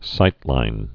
(sītlīn)